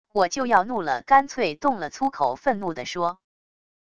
我就要怒了干脆动了粗口愤怒的说wav音频